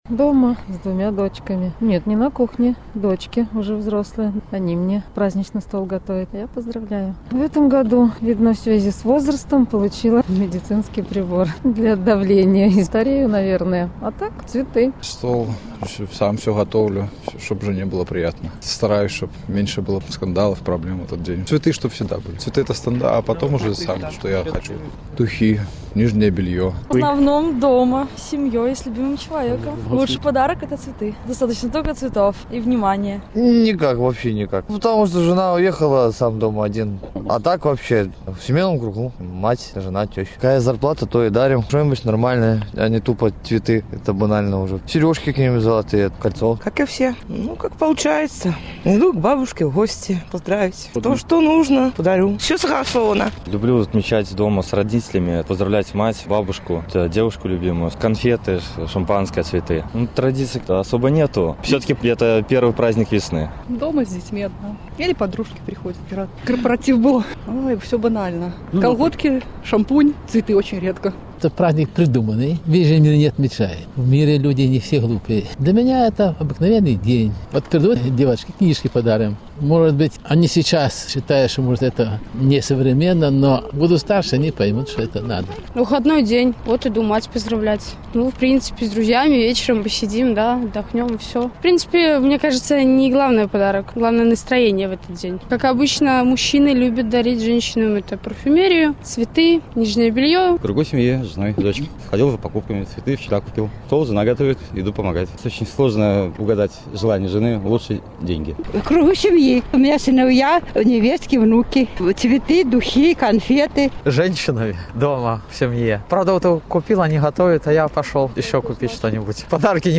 Адказвалі жыхары Магілёва.